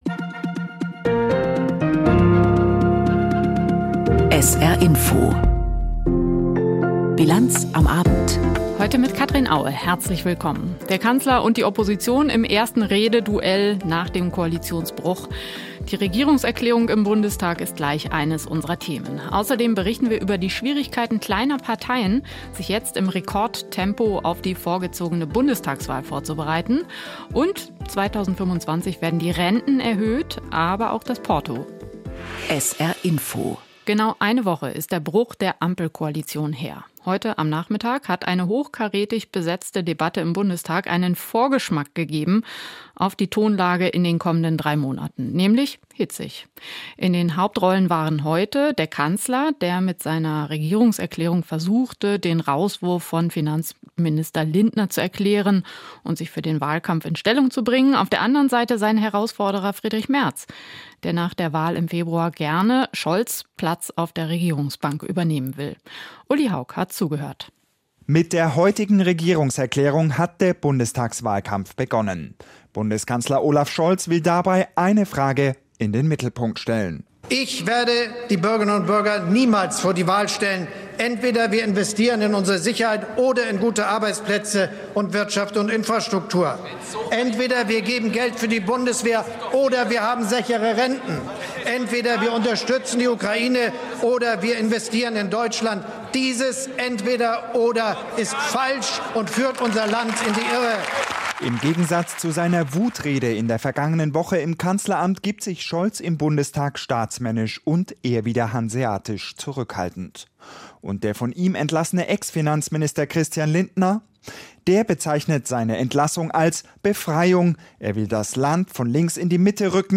Aktuelles und Hintergrnde zu Entwicklungen und Themen des Tages aus Politik, Wirtschaft, Kultur und Gesellschaft in Berichten und Kommentaren.